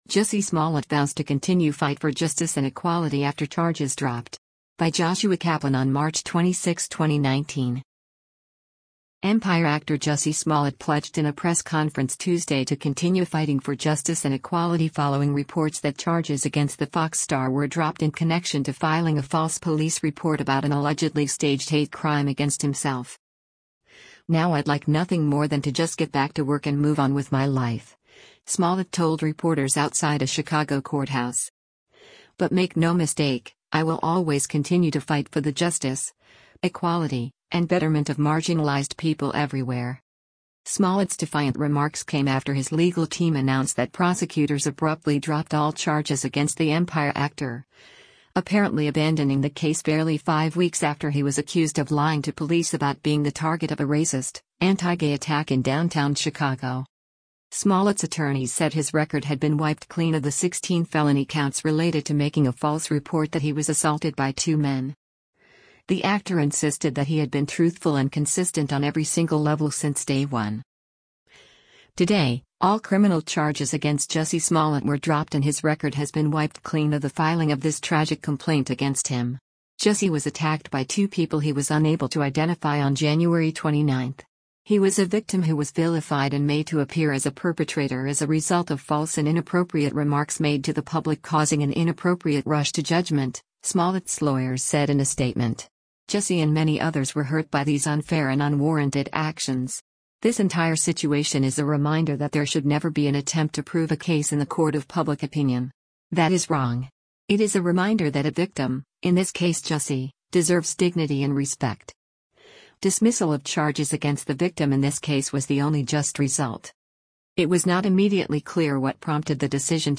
Empire actor Jussie Smollett pledged in a press conference Tuesday to continue fighting for “justice” and “equality” following reports that charges against the Fox star were dropped in connection to filing a false police report about an allegedly staged hate crime against himself.